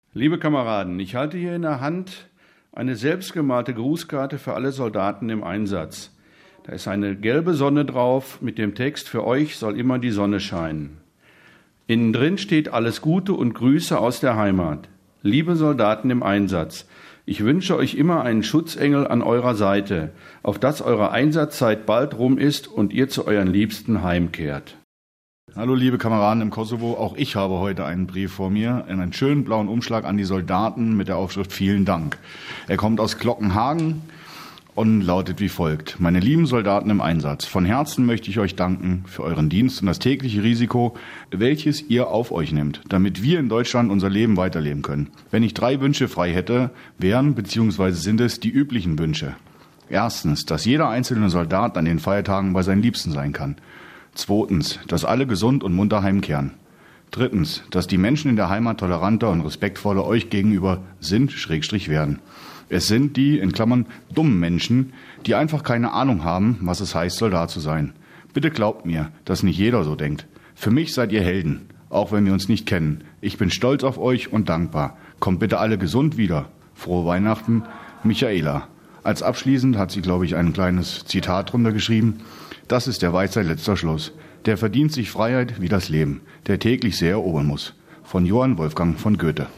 Das Team von Radio Andernach schnappte sich die Briefe, ein Mikro und ging mit prallgefüllten Rücksäcken durch das Feldlager. Die Einsatzsoldaten lasen selbst die lieben Worte der Menschen, die Zuhause in Deutschland an sie dachten.